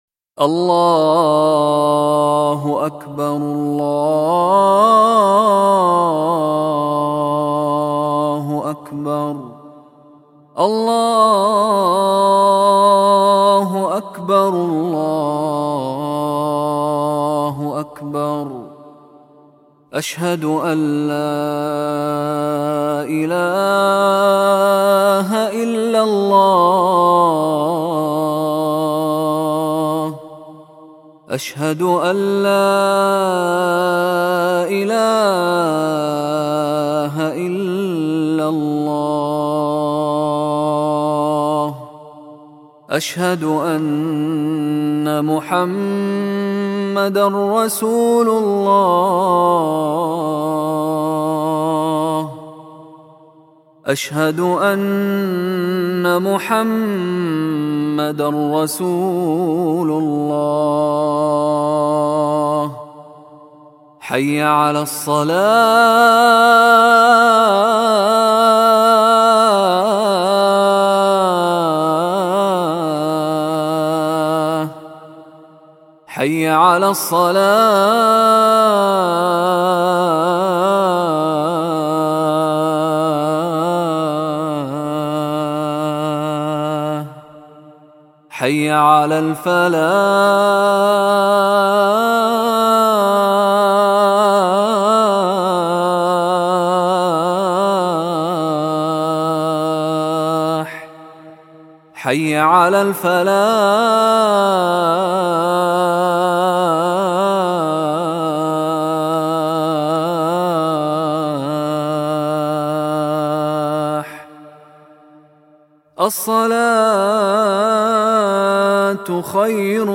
Mishary Rashad Fajr Adhan
Athan_1_alafasy_Fajr.mp3